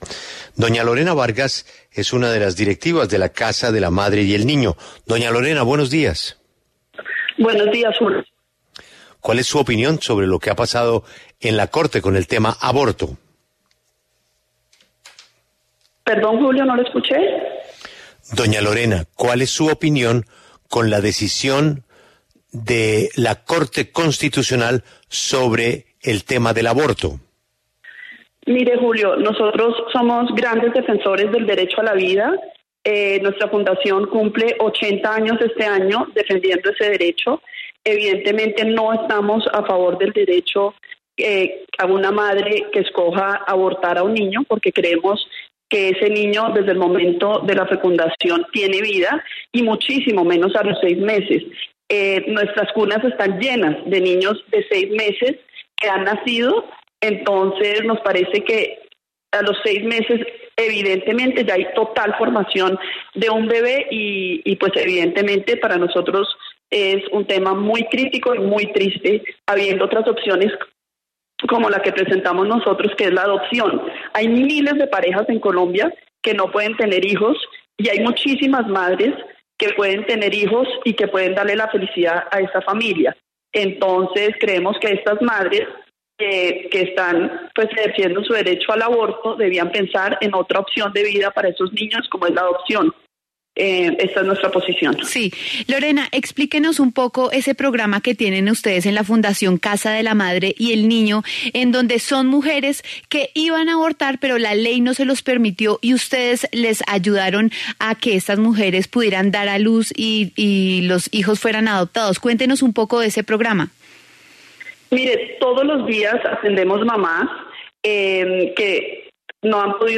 La W Radio